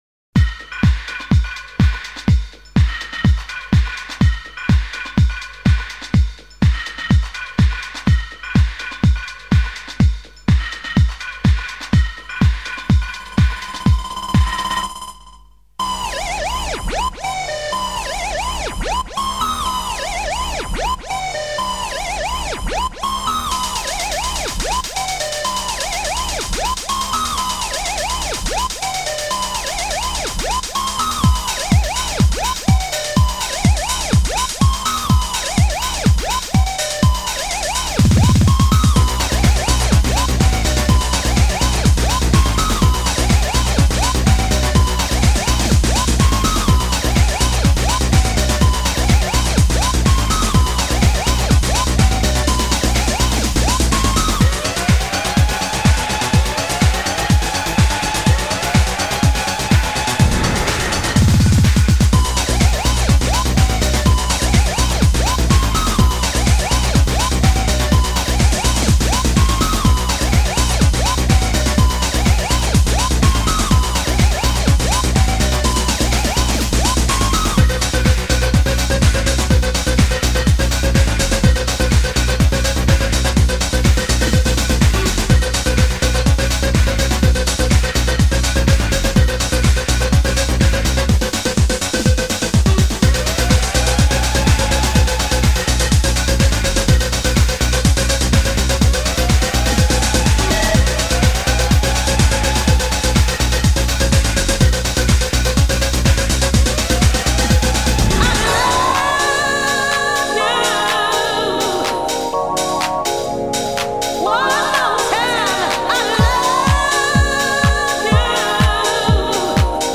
Techno_Trax
Techno_Trax.mp3